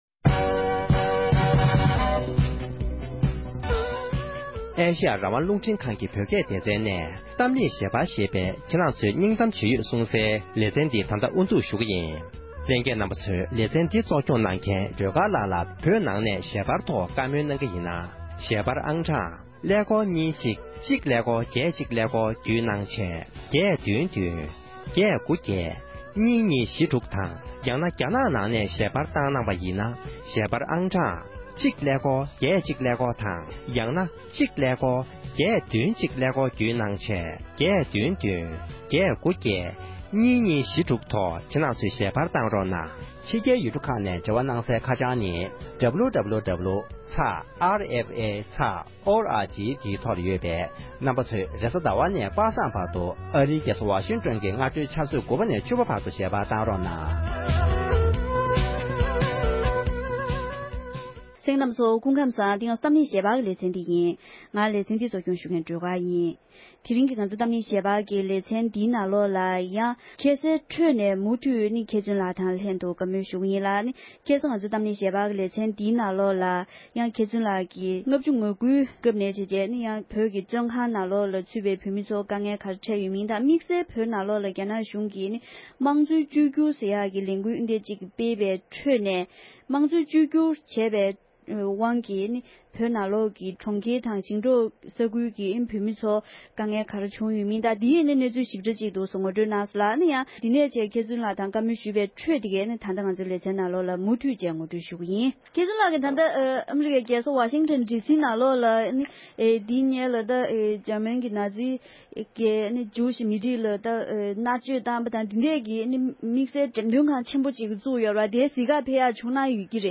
༄༅༎དེ་རིང་གི་གཏམ་གླེང་ཞལ་པར་གྱི་ལེ་ཚན་ནང་དུ་འདས་པའོ་ལོ་ངོ་ལྔ་བཅུའི་རིང་བོད་ནང་གི་བོད་མི་ཚོས་རྒྱ་ནག་གཞུང་གི་སྲིད་ཇུས་འདྲ་མིན་འོག་དཀའ་སྡུག་ཚད་མེད་མྱོང་དགོས་བྱུང་ཡོད་པའི་སྐོར་ལ་ཕྱིར་དྲན་ཞུས་པའི་ལེ་ཚན་གཉིས་པར་གསན་རོགས༎